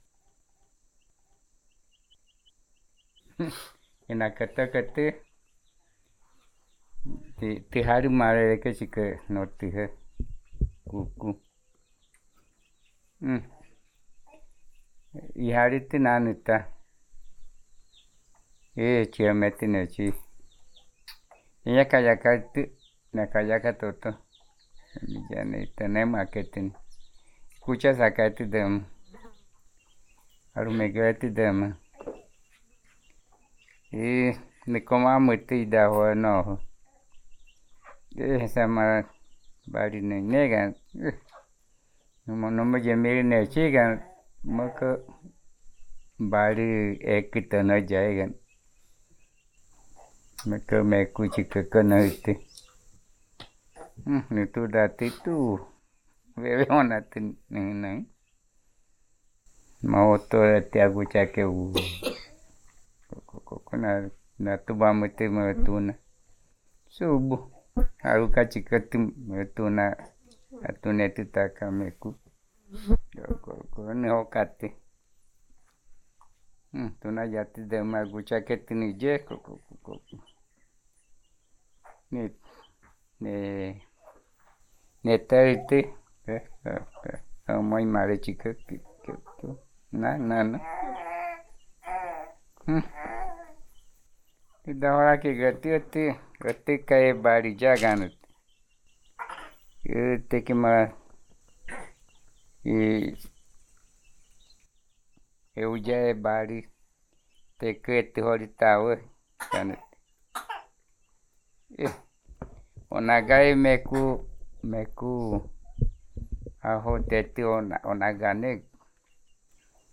Puerto Nare, Guaviare